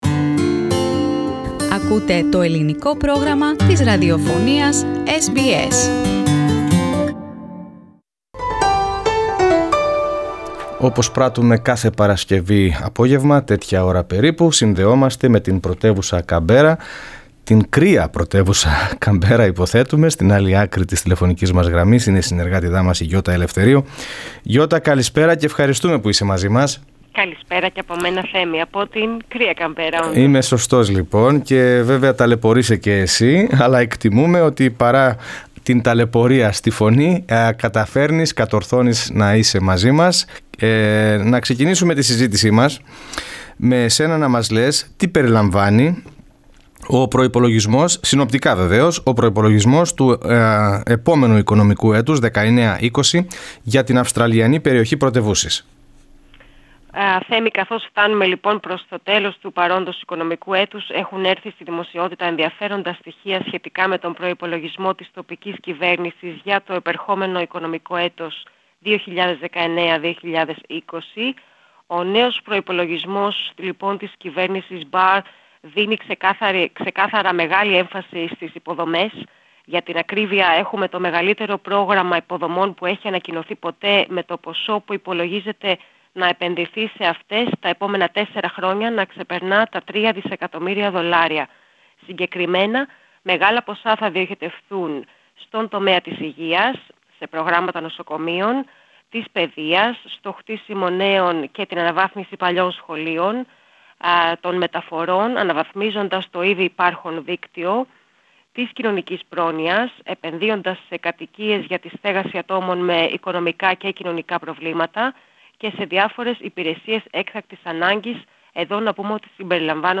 The new Budget from Andrew Barr's government in ACT will spend over $3 billion in infrastructure works. However, this is expected to deliver a deficit of $89 million. More on this story in our report from Canberra.